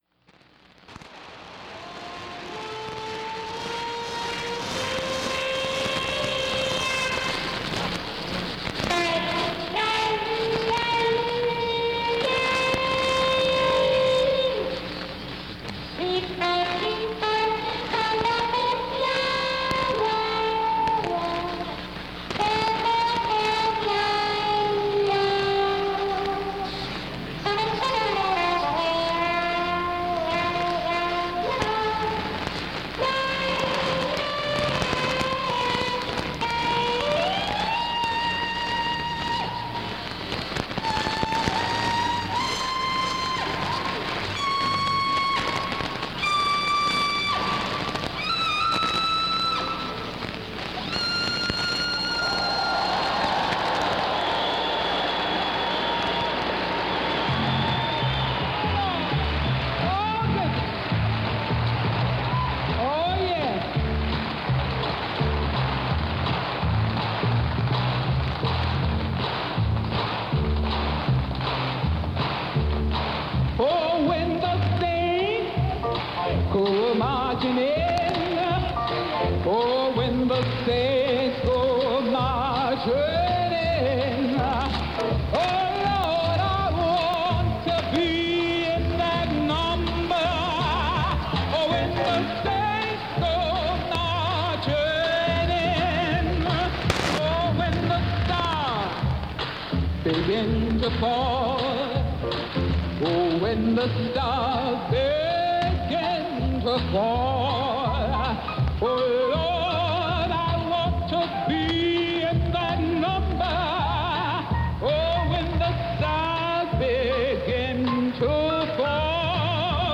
Radio Receptor Kenwood R-600 Antena hilo largo (10 metros a una altura de 6 metros)